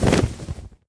sounds / mob / enderdragon / wings2.mp3
wings2.mp3